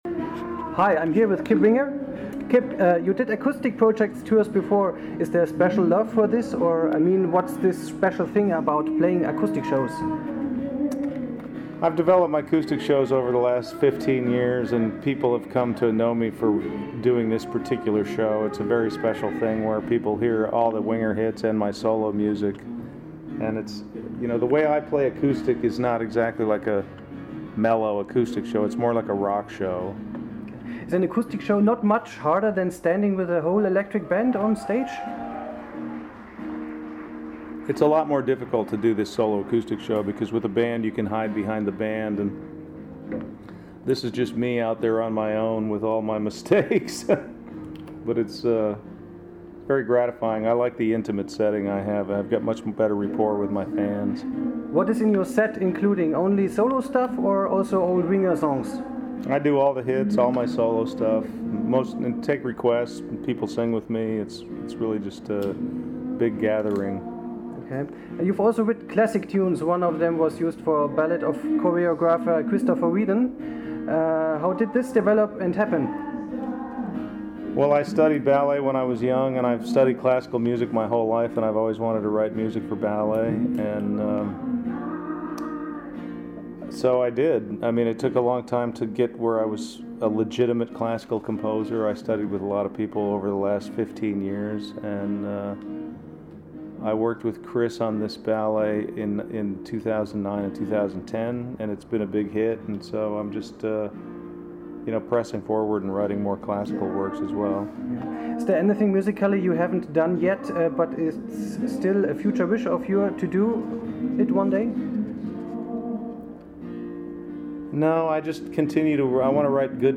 Ein Interview mit Kip Winger gibts hier: